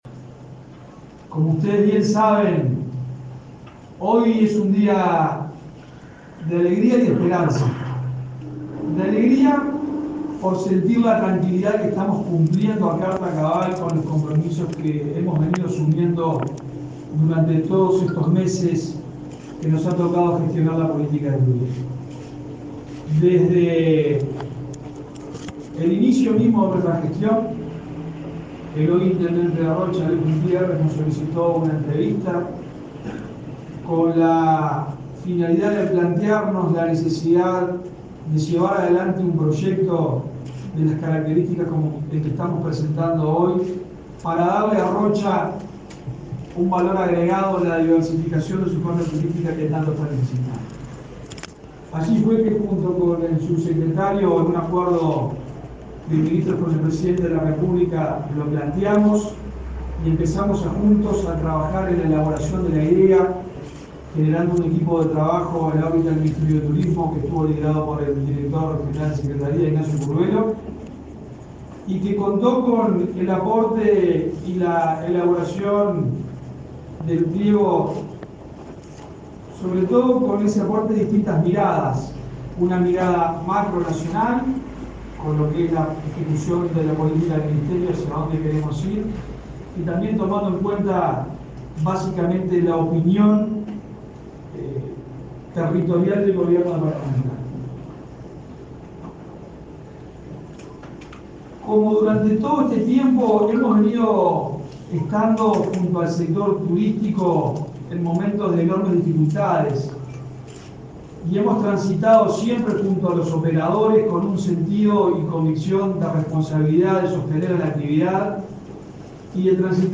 Declaraciones del ministro de Turismo, Germán Cardoso, en el lanzamiento del llamado para la construcción de hotel 5 estrellas en Rocha.